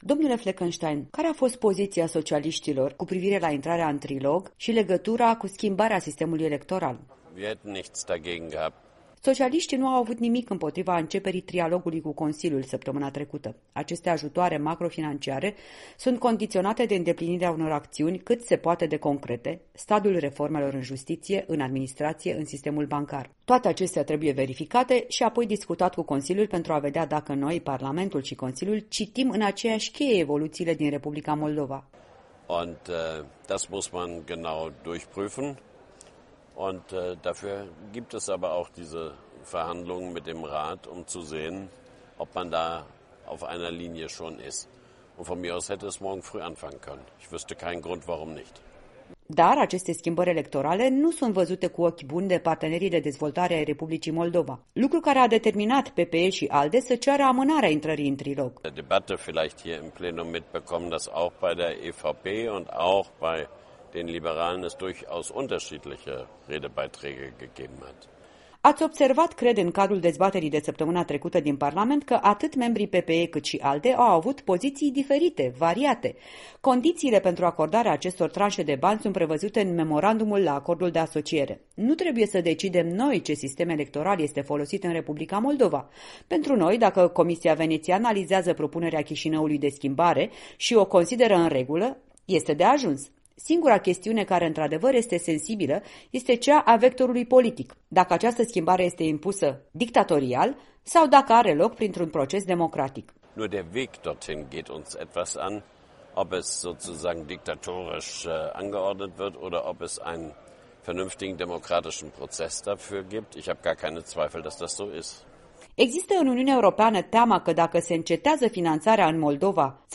Un interviu cu europalamentarul german socialist pe tema ajutorului UE pentru Moldova.